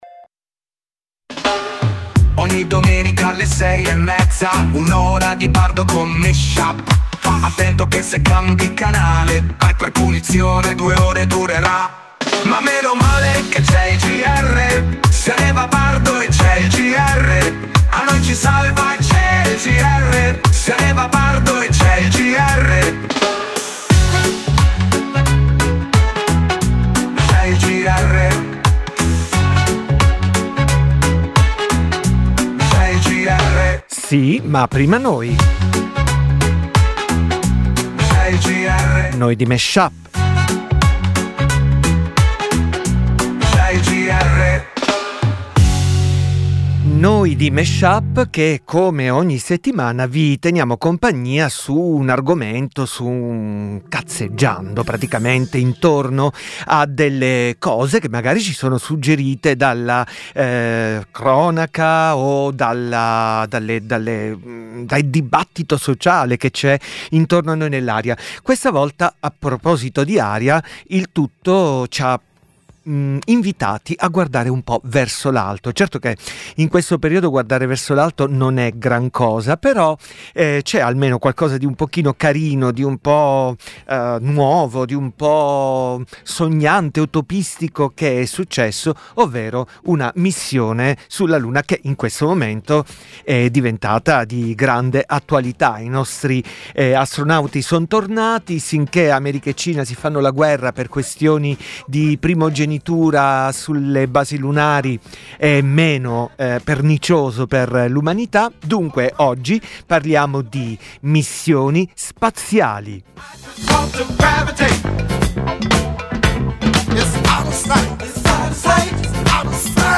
dj set tematico di musica e parole